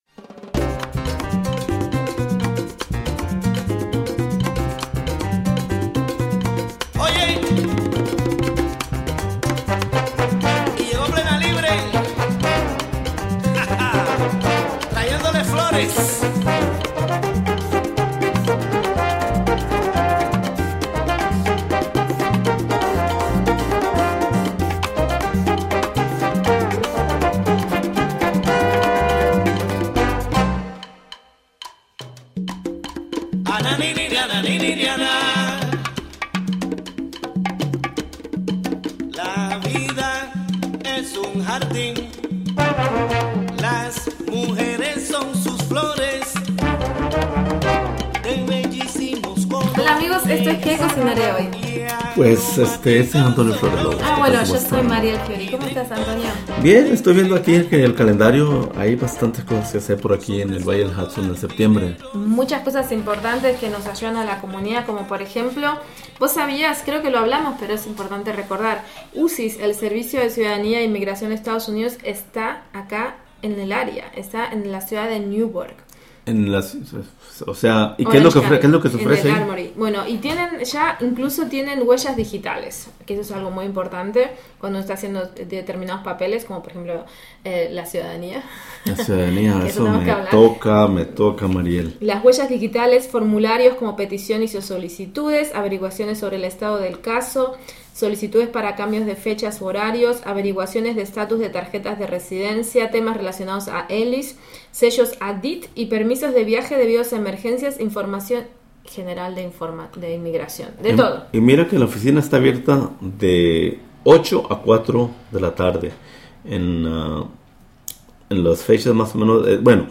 11am Interview with Spanish journalist Guillermo Fesser...